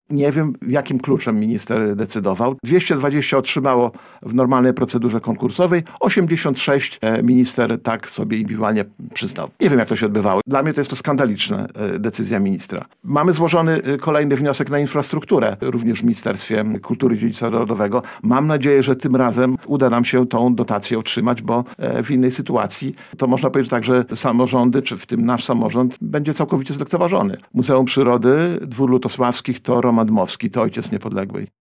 Nie wiem jakimi kryteriami kierował się Pan Minister – mówi Starosta Łomżyński